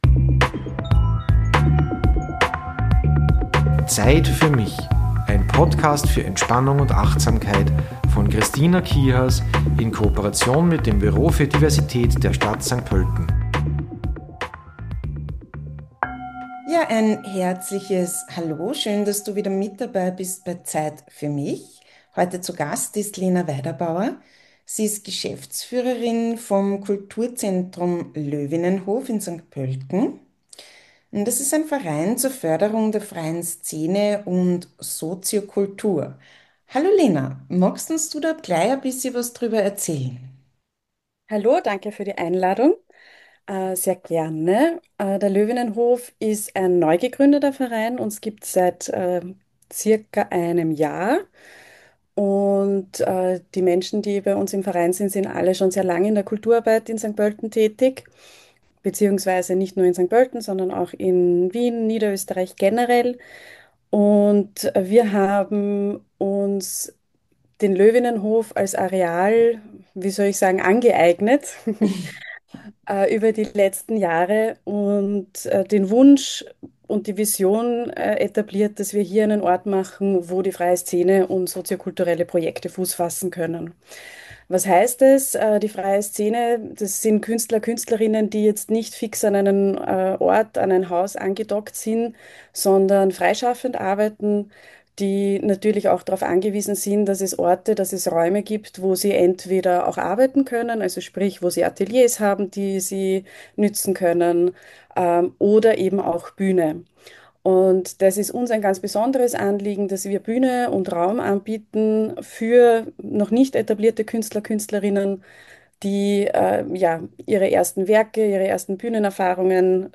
Im 2. Teil von „Zeit für mich“ erwartet dich eine Gesichtsmassage, die Vagus stimuliert, und dich entspannt.